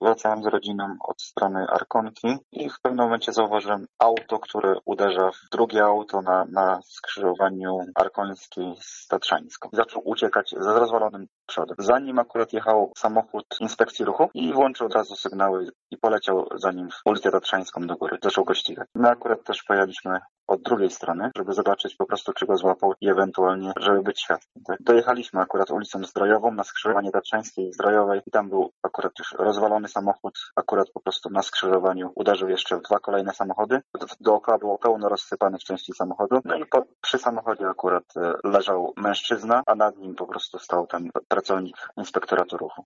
świadek zdarzenia.